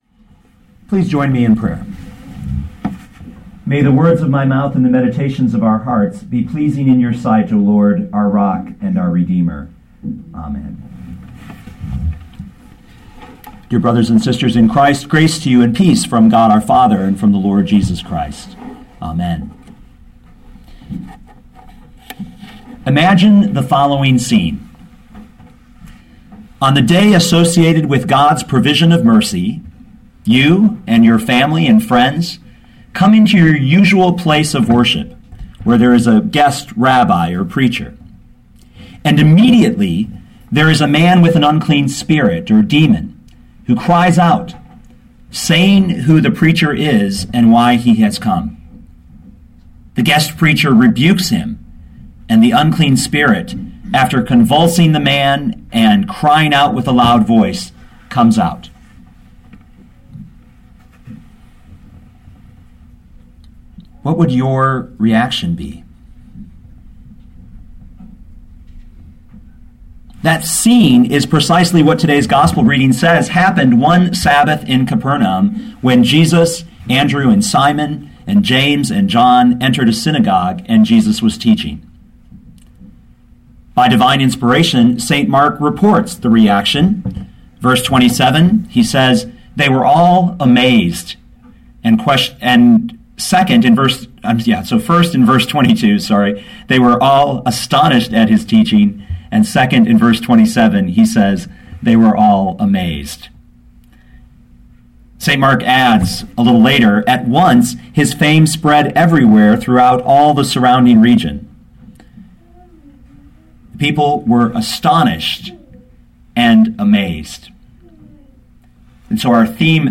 4th Sunday after Epiphany